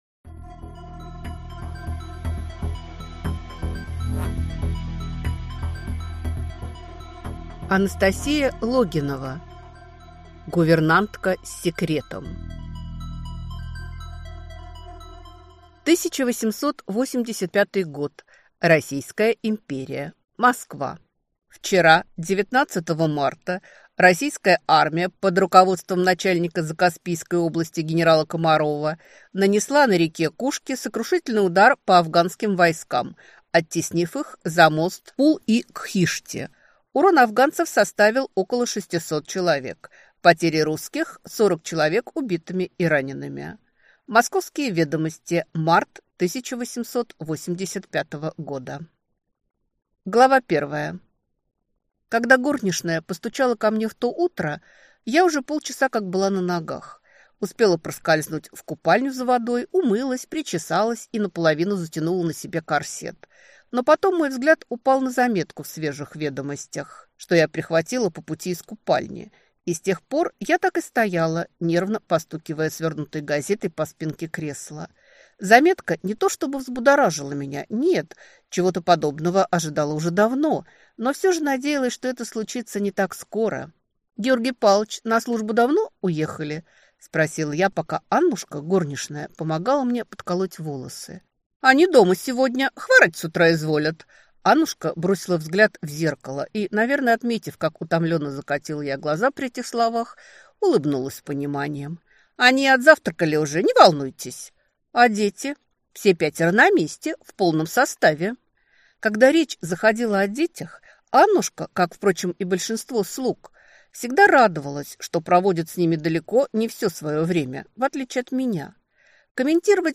Аудиокнига Гувернантка с секретом | Библиотека аудиокниг